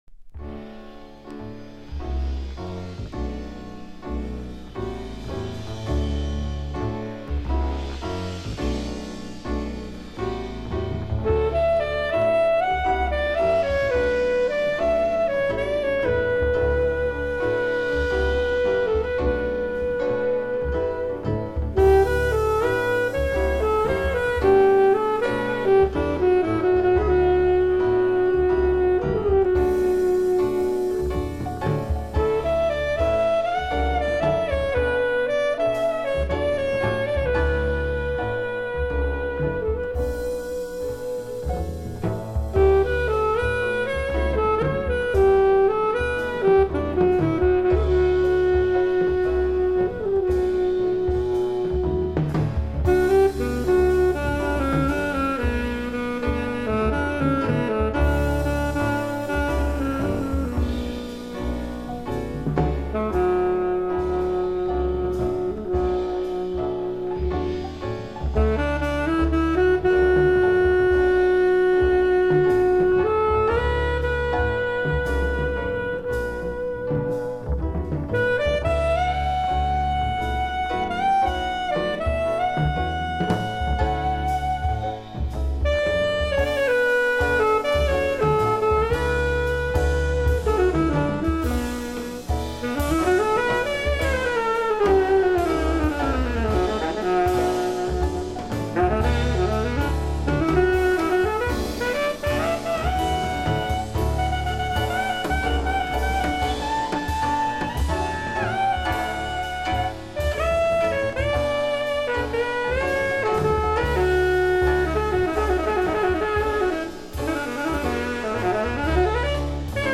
alto saxophone/flute*
piano
bass
drums
Recorded: February at RG. Studio, Vicenza, Italy